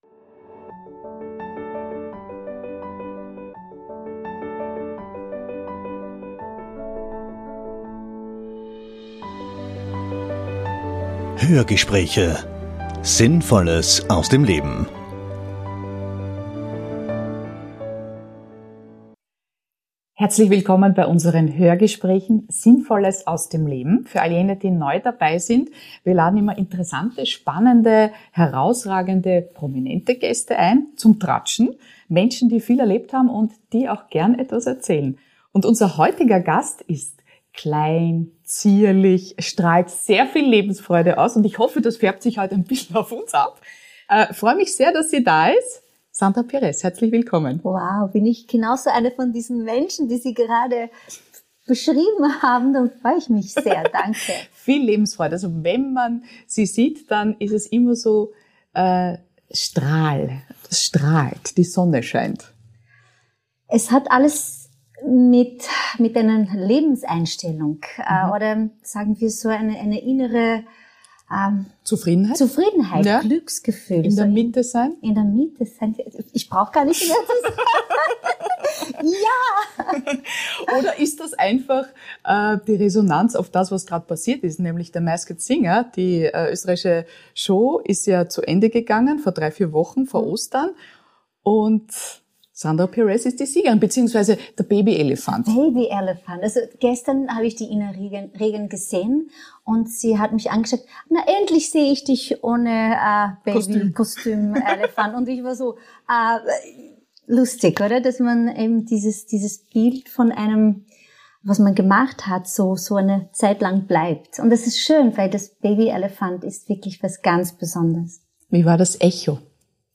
Beschreibung vor 4 Jahren Ihr Strahlen bestimmt das Interview mit der Ausnahmekünstlerin Sandra Pires. Wir sprechen mit ihr über das Geheimnis ihres Erfolges, ihre Lebenseinstellung und was sie als Nächstes vorhat. Wie gut sie auf ihren ausgeprägten Gehörsinn aufpasst und dass es Kollegen gibt, die ihre Karriere aufgrund eines Hörverlusts beenden mussten, auch darüber erfahren wir von ihr im spannenden Interview.